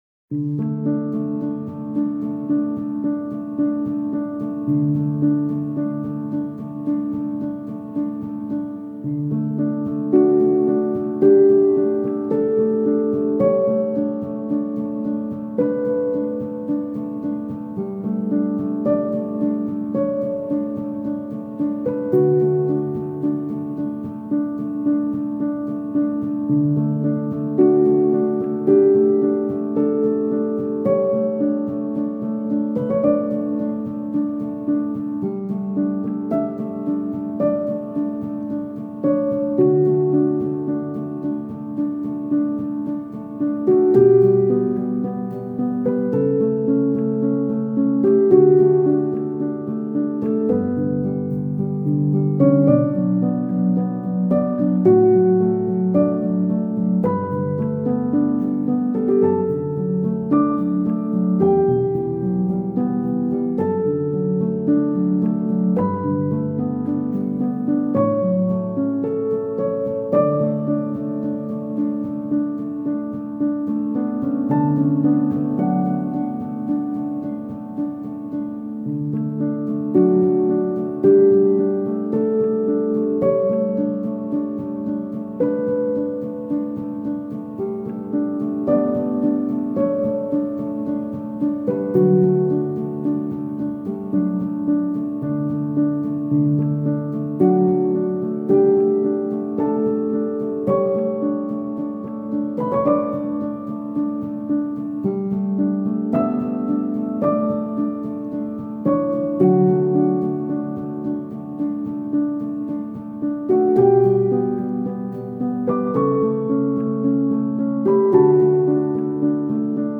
آرامش بخش , پیانو , عصر جدید , موسیقی بی کلام